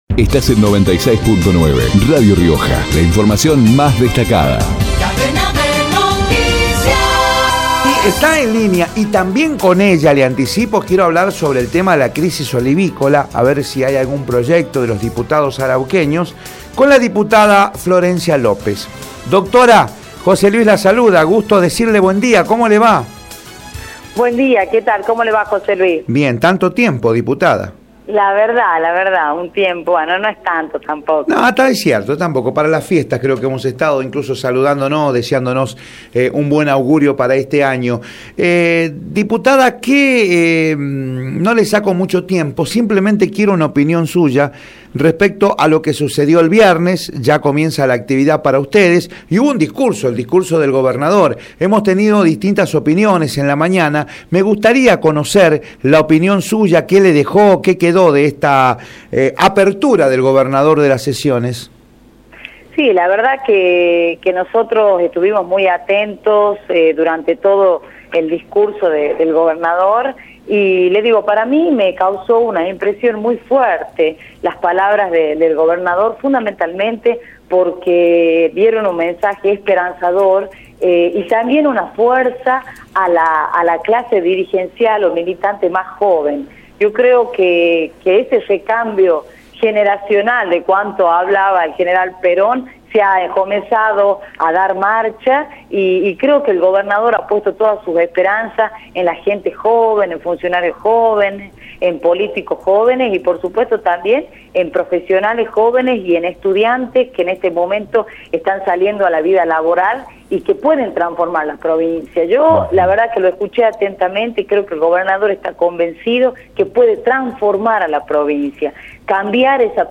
Florencia López, diputada provincial, por Radio Rioja